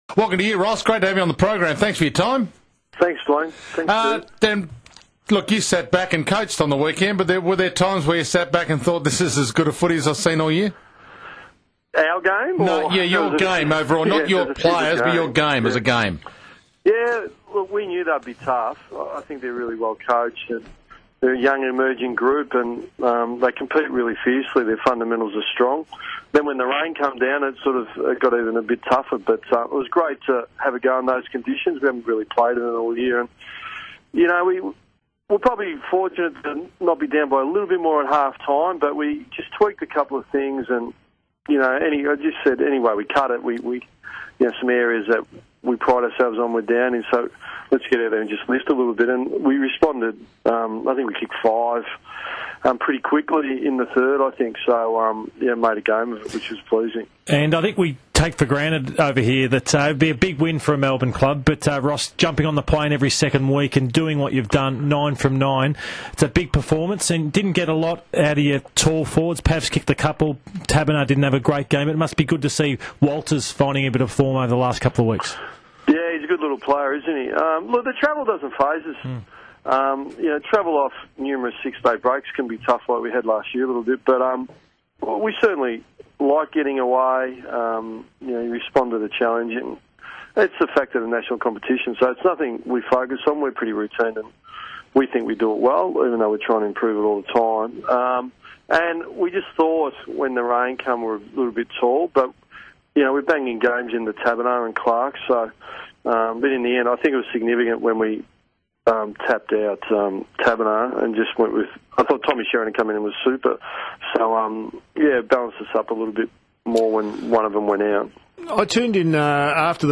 Ross Lyon speaks to Sports Today on 3AW